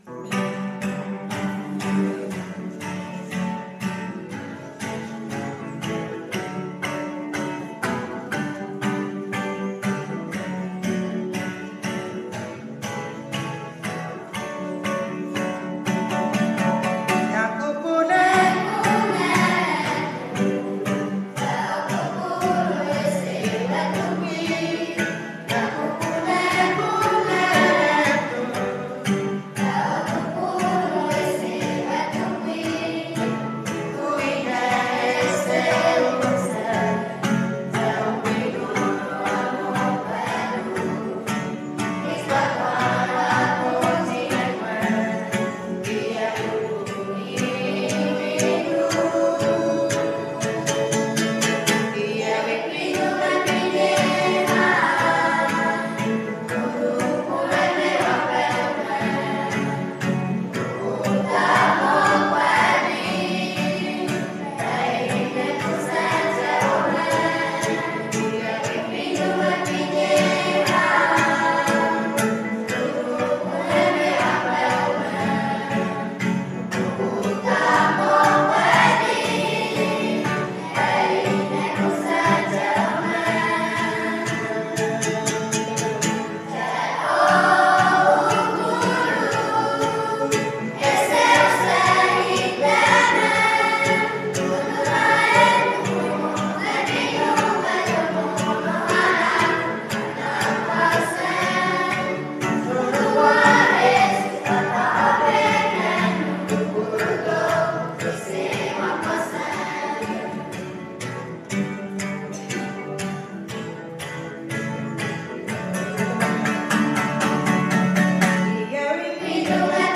P Kids Choir
I assume he just used a cell phone to record it.